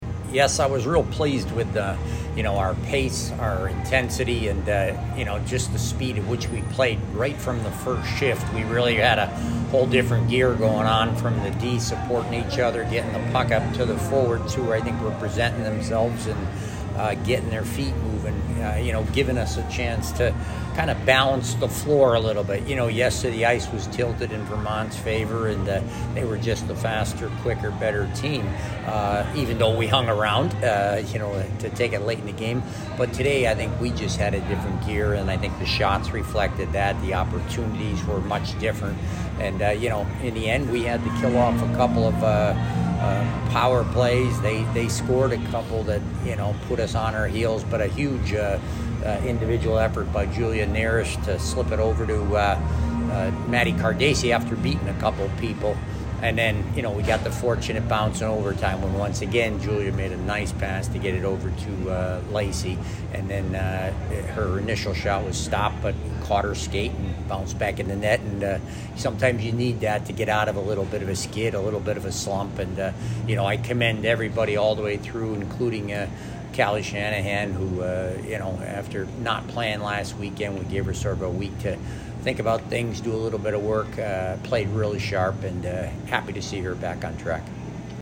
Women's Ice Hockey / Vermont Postgame Interview (11-12-22)